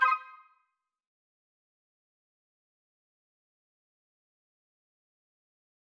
confirm_style_4_002.wav